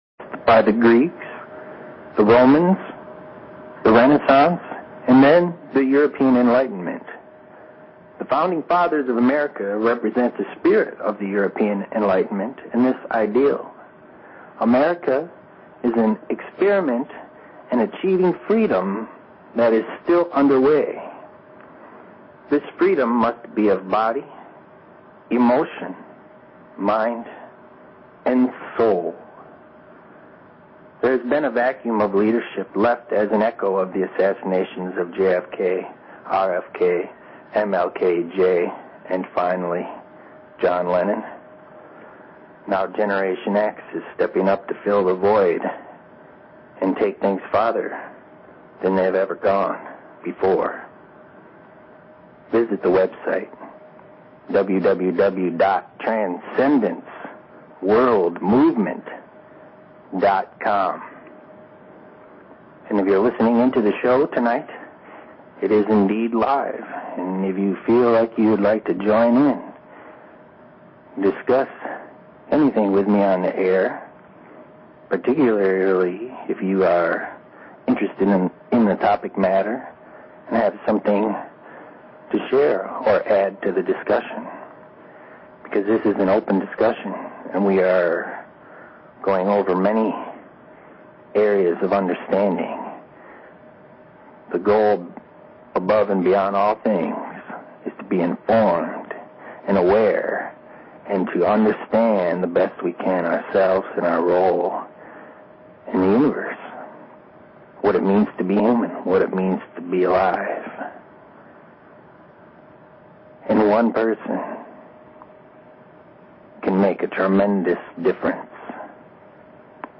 Talk Show Episode, Audio Podcast, TWM and Courtesy of BBS Radio on , show guests , about , categorized as